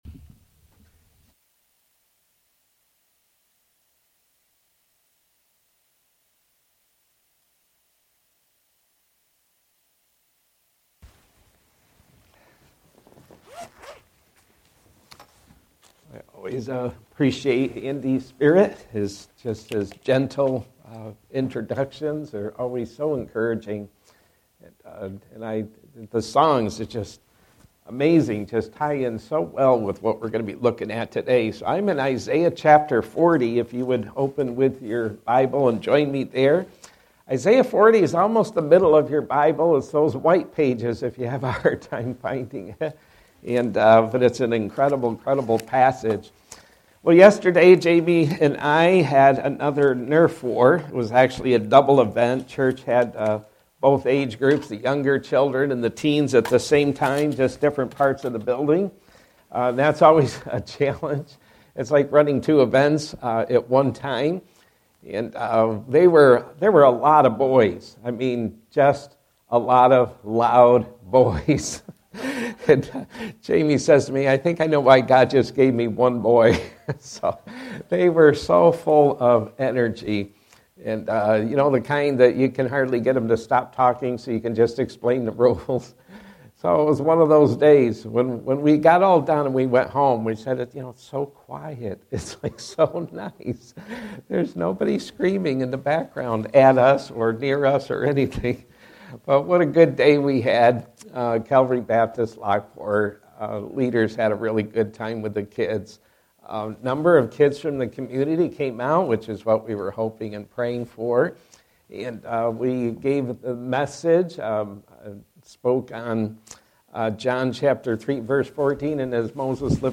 Sermons by First Baptist Church of Elba